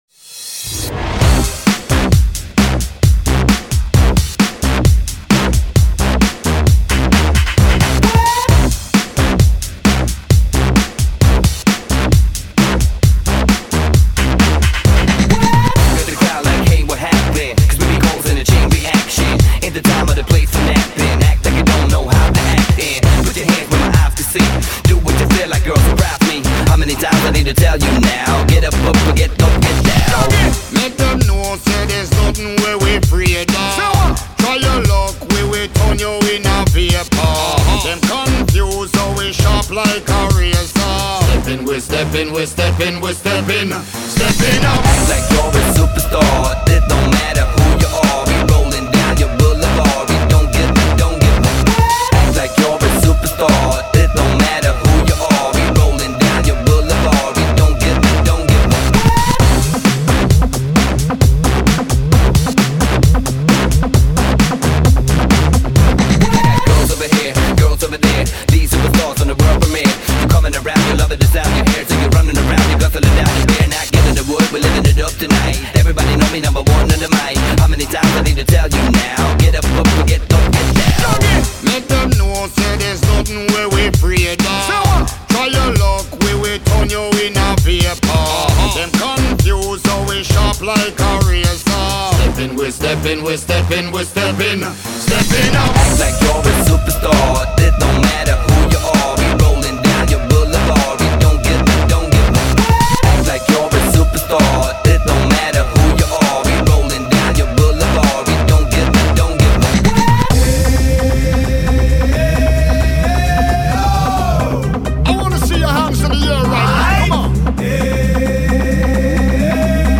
Dance music